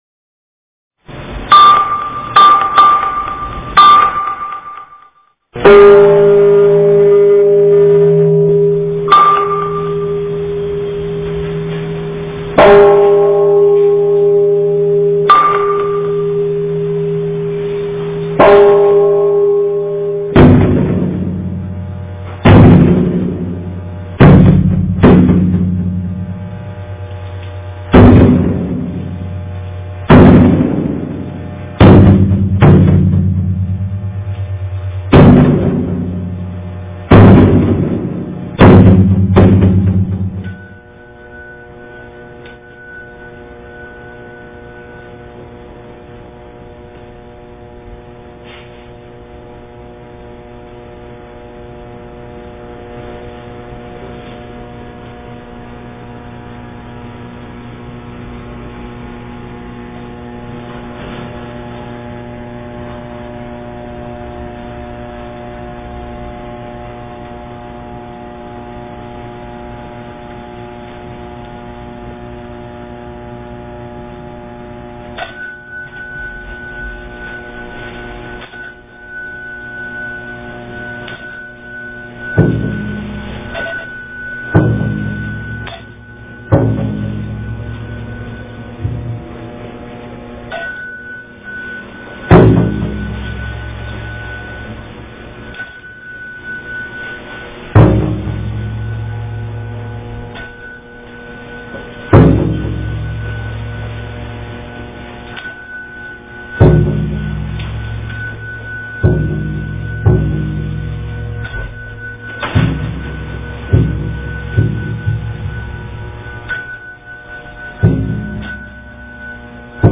早课--大华严寺
早课--大华严寺 经忏 早课--大华严寺 点我： 标签: 佛音 经忏 佛教音乐 返回列表 上一篇： 山僧--佚名 下一篇： 早课--圆光佛学院男众 相关文章 大乘金刚般若宝忏法卷中--金光明寺 大乘金刚般若宝忏法卷中--金光明寺...